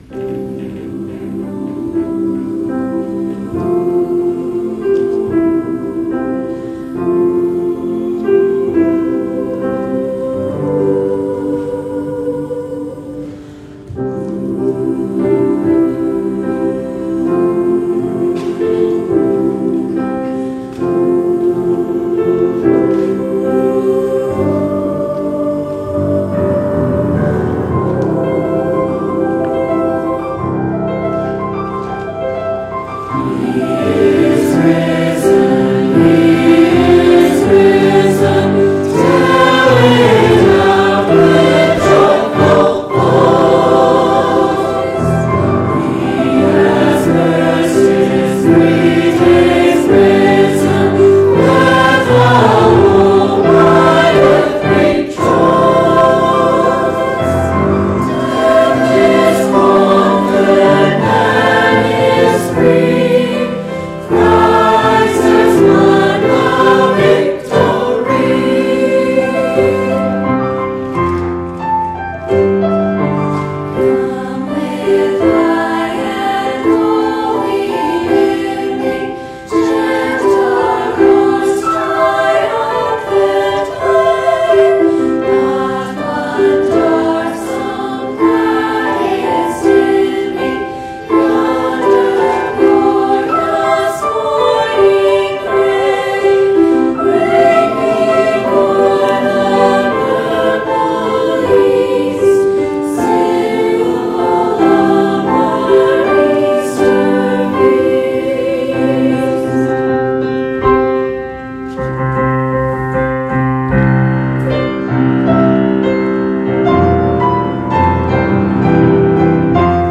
Voicing/Instrumentation: SATB We also have other 41 arrangements of " He is Risen ".
Choir with Soloist or Optional Soloist